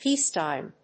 音節péace・tìme 発音記号・読み方
/ˈpiˌstaɪm(米国英語), ˈpi:ˌstaɪm(英国英語)/